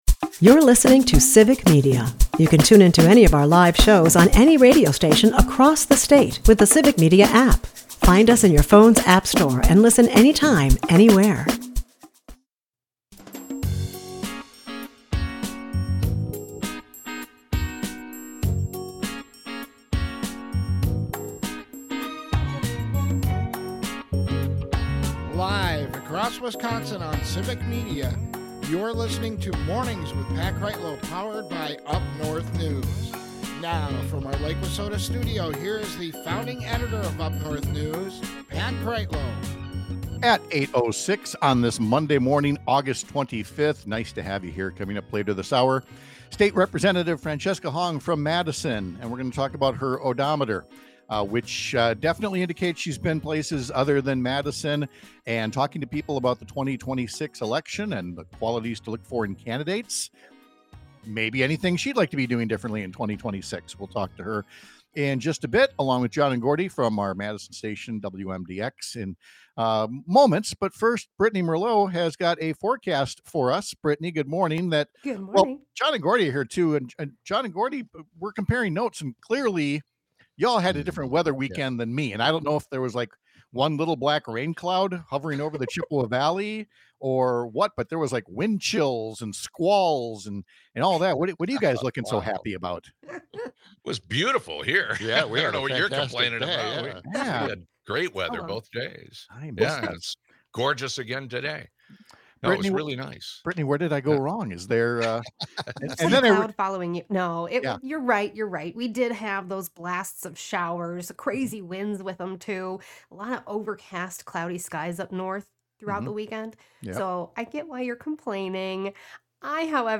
Guests: Francesca Hong